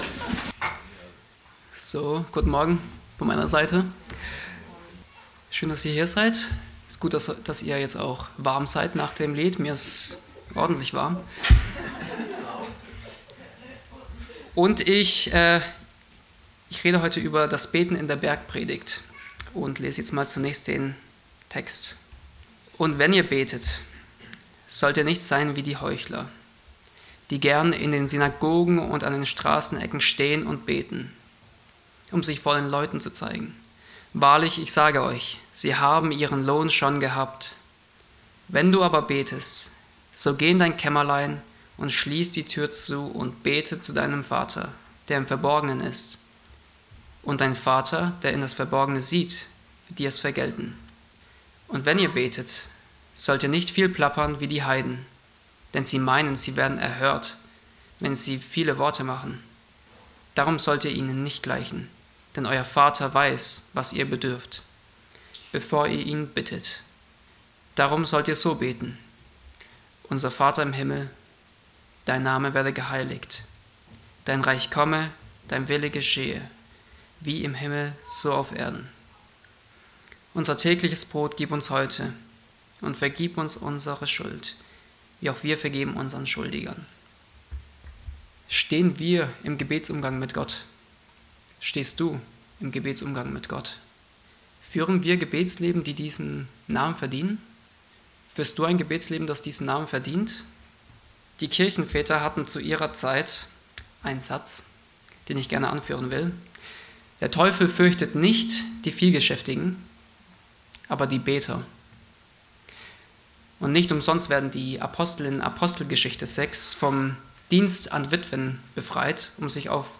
Predigt vom 09.